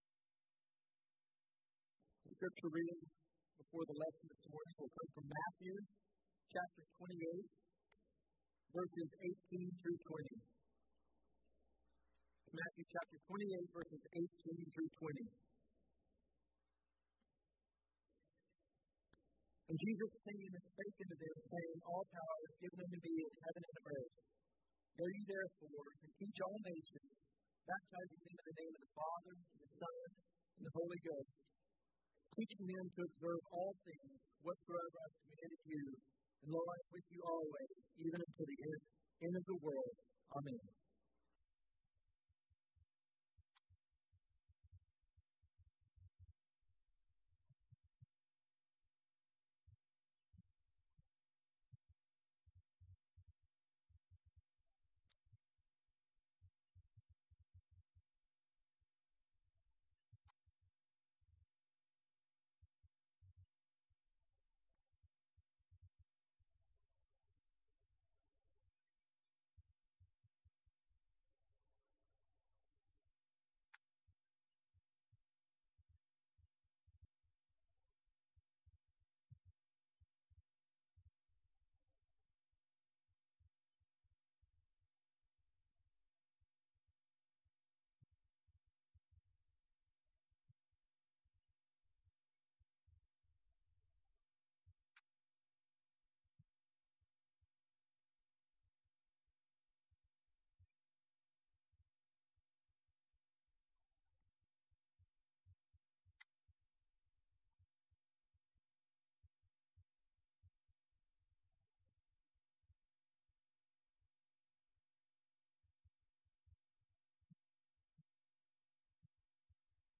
Event: 5th Annual Arise Workshop
lecture